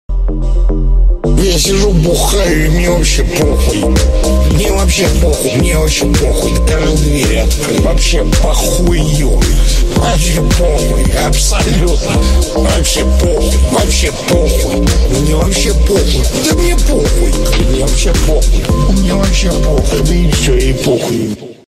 Рингтоны Ремиксы